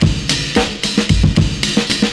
112CYMB08.wav